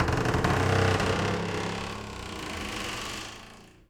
door_A_creak_01.wav